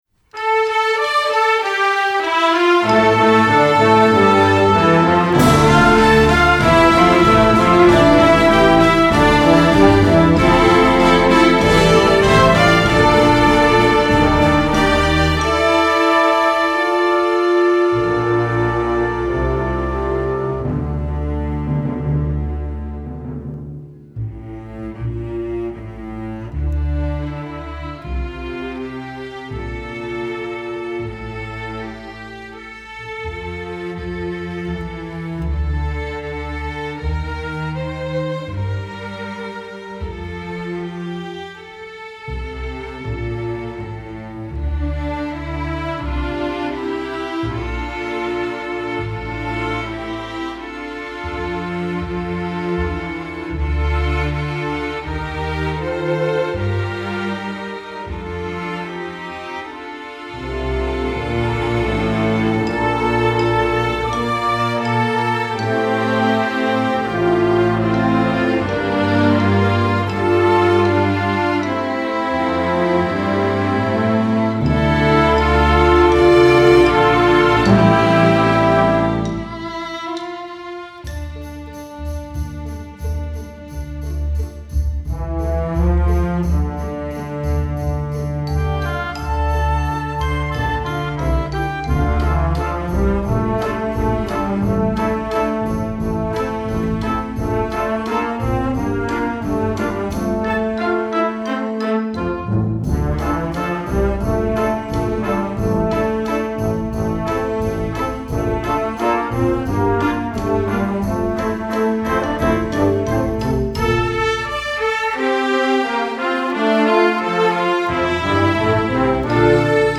Instrumentation: full orchestra
hymn, sacred